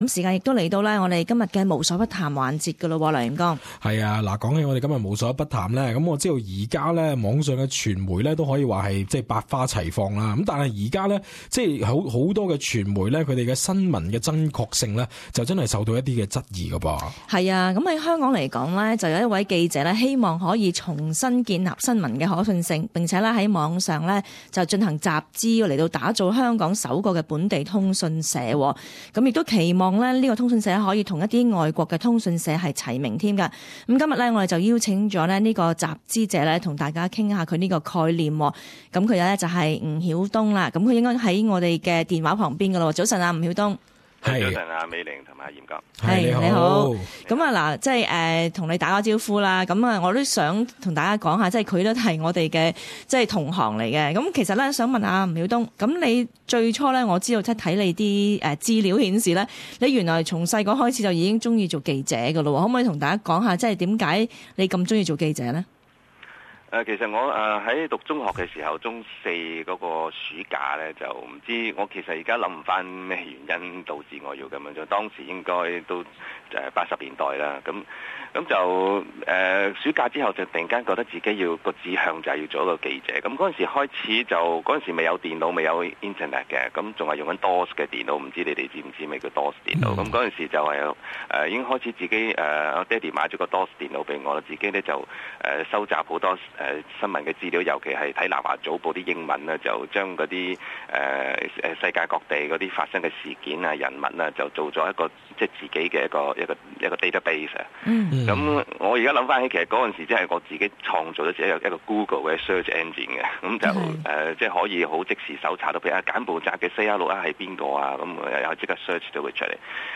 Celebrity Talk